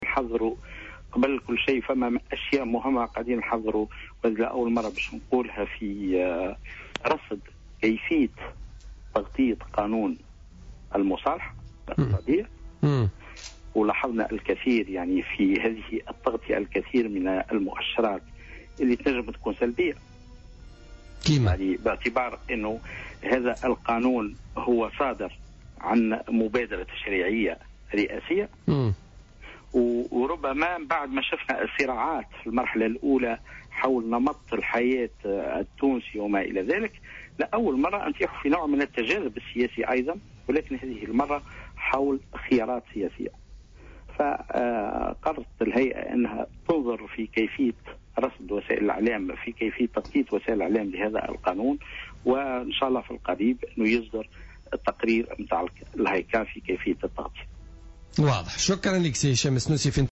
قال عضو الهيئة العليا المستقلة للاتصال السمعي البصري "الهايكا"،هشام السنوسي في مداخلة له اليوم في برنامج "بوليتيكا" إن الهيئة ستصدر قريبا تقريرا لرصد كيفية تناول وسائل الإعلام لقانون المصالحة.